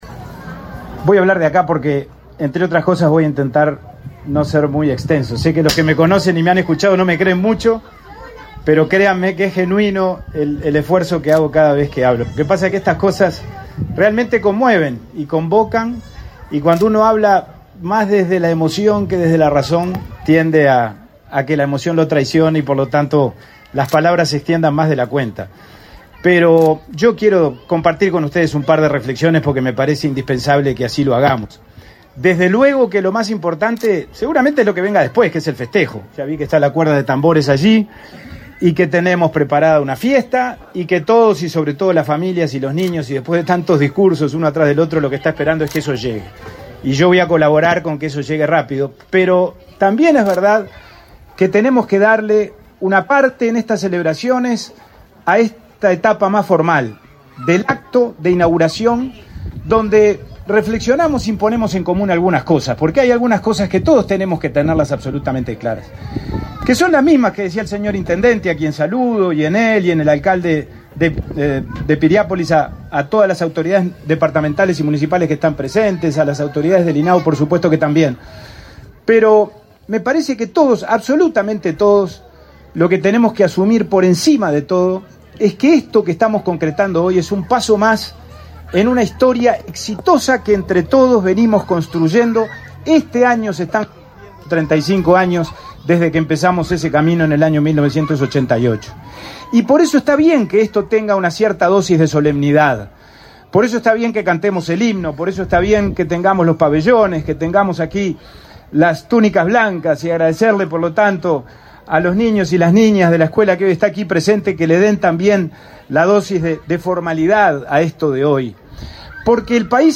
Palabras del presidente del INAU, Pablo Abdala
El Instituto del Niño y Adolescente del Uruguay (INAU) inauguró, este 21 de abril, un CAIF en Maldonado, al que asisten 150 niños.